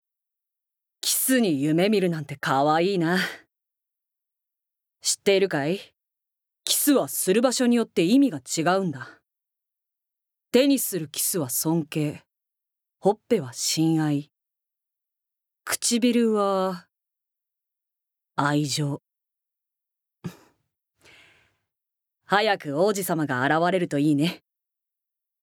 Voice Sample
ボイスサンプル
セリフ４